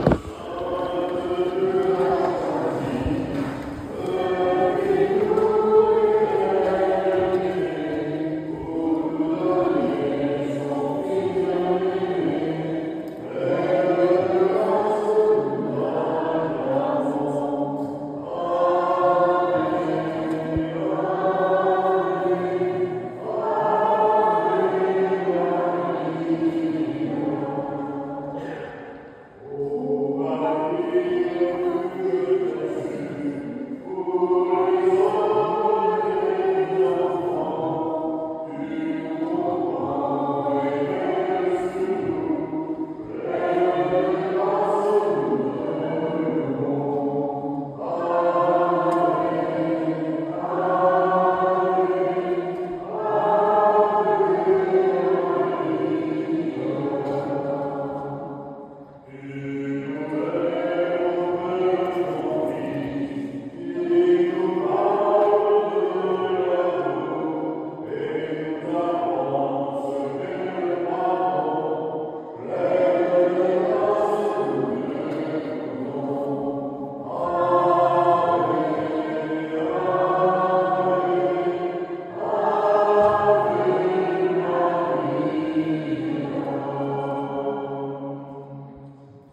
Une messe a eut lieu dans cette église le 15/08/2025 à 10H30
un cinquantaine de fidèles ont participé à cette office